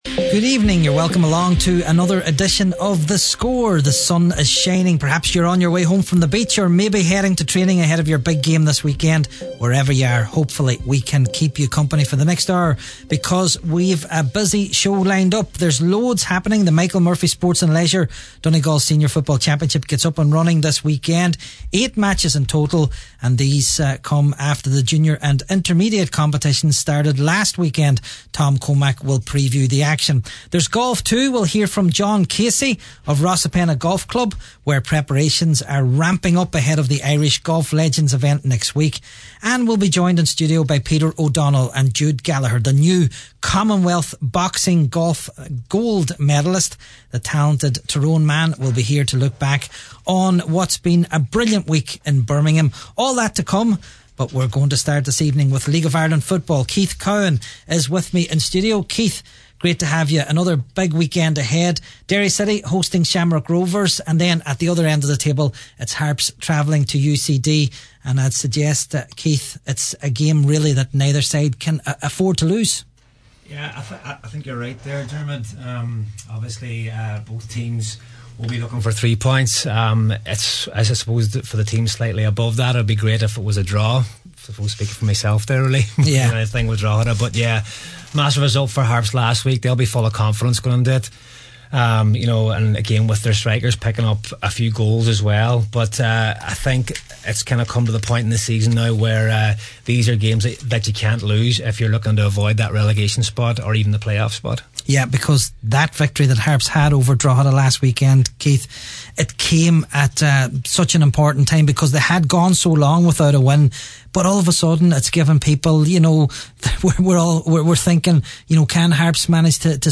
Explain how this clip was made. was in studio to offer his thoughts on another big weekend in the League of Ireland